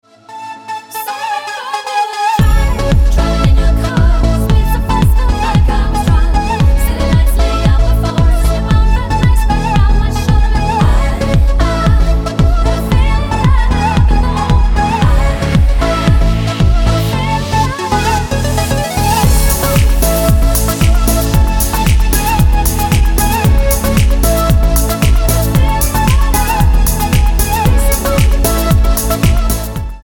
Tonart:A mit Chor